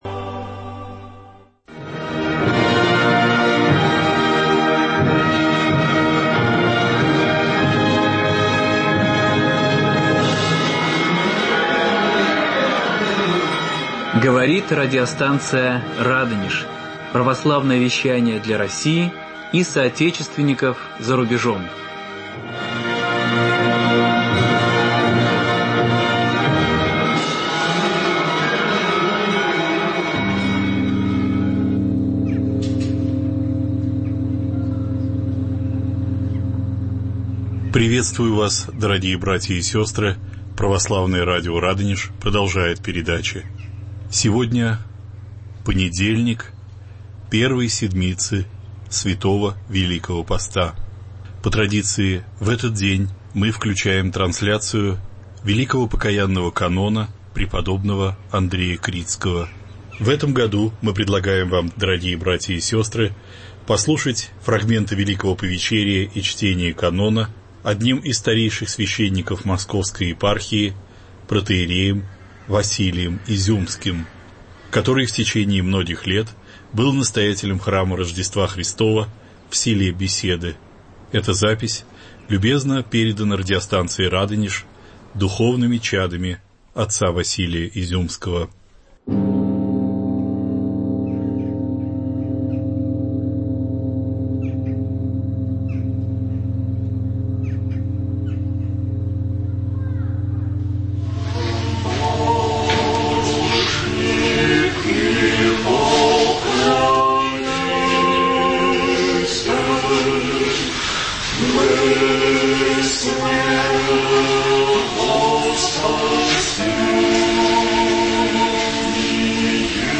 Трансляция фрагментов Великого Повечерия и чтения Великого Покаянного канона прп. Андрея Критского